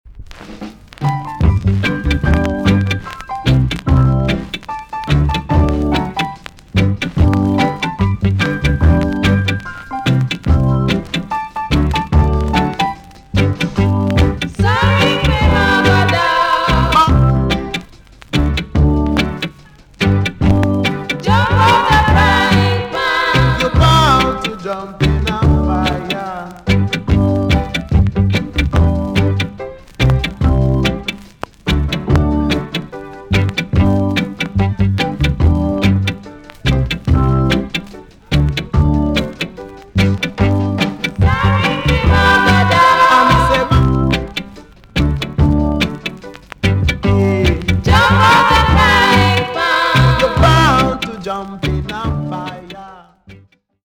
TOP >REGGAE & ROOTS
B.SIDE Version
VG+ 少し軽いチリノイズがあります。